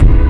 Kick (Nightmare).wav